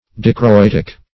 Dichroitic \Di`chro*it"ic\, a.